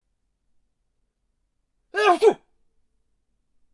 三声小咳嗽 " D B COUG H 003
描述：在录制原声吉他音轨时捕捉到的一个小而粗糙的咳嗽声。
标签： 感冒 咳嗽 锉刀 打喷嚏 咽喉
声道立体声